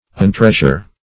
Search Result for " untreasure" : The Collaborative International Dictionary of English v.0.48: Untreasure \Un*treas"ure\, v. t. [1st pref. un- + treasure.]